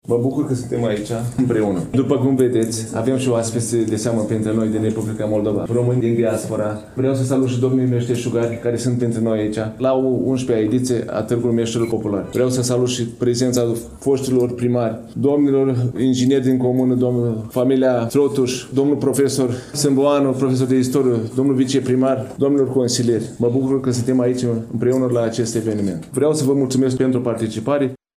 Stimați prieteni, întâi de toate, de la deschiderea Expoziției „Veșmintele Satului Românesc”, ascultăm discursul primarului Comunei Ruginoasa, Ionuț Constantin Pristăvița, cel care adresează mulțumirile aferente tuturor celor care au făcut posibilă oraganizarea manifestărilor culturale de zilele trecute.